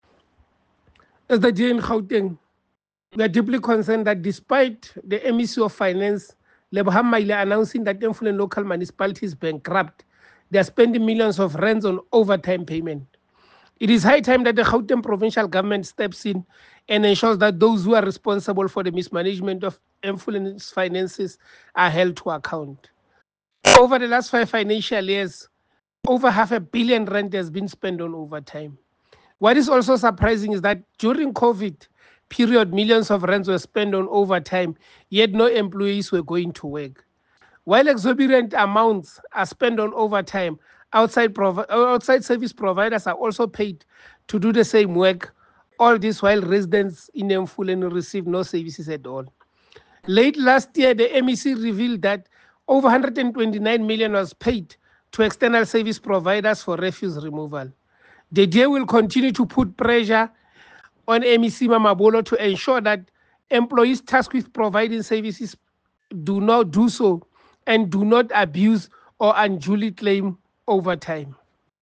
Note to Editors: Please find a soundbite in English from DA MPL, Kingsol Chabalala